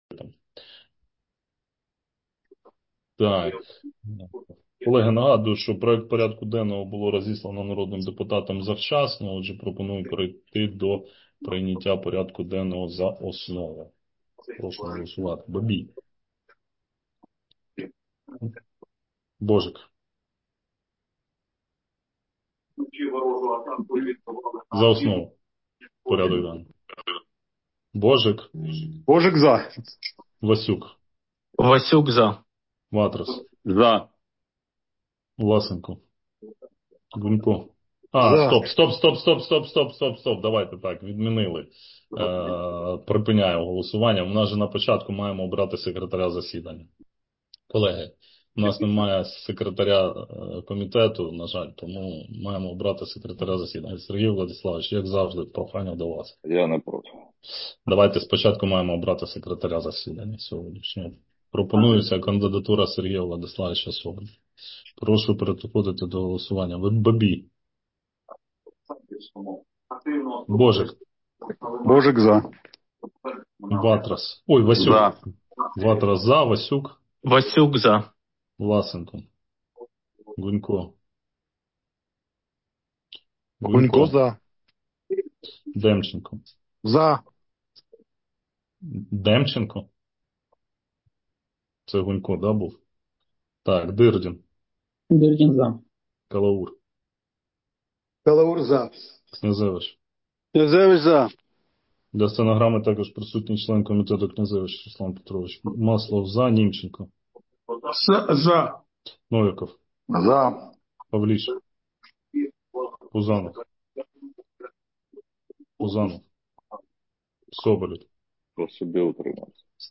Назва файлу - Аудіозапис засідання Комітету від 12 січня 2026 року